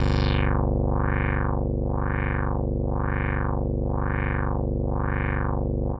Index of /90_sSampleCDs/Trance_Explosion_Vol1/Instrument Multi-samples/LFO Synth
G1_lfo_synth.wav